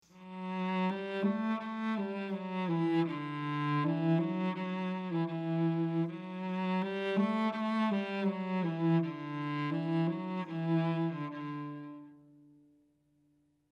Виолончель небольшая мелодия